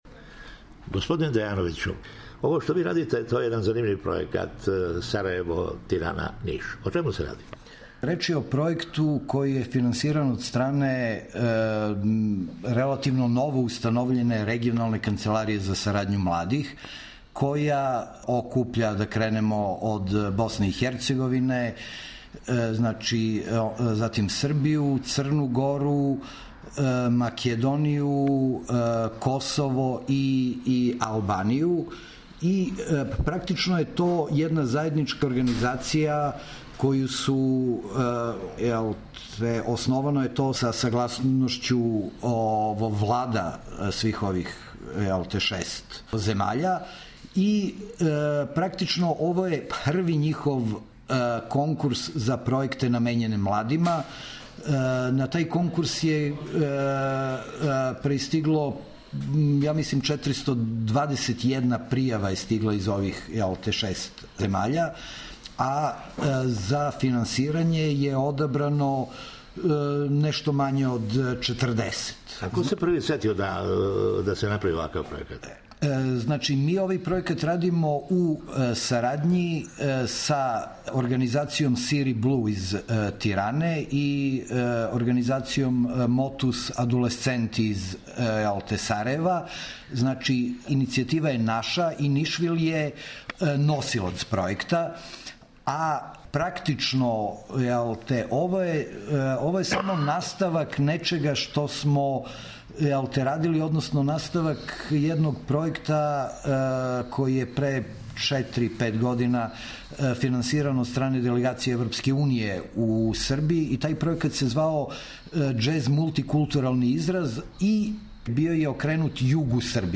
О џезерском доприносу помирењу, сарадњи и бољем разумевању на Балкану наш сарадник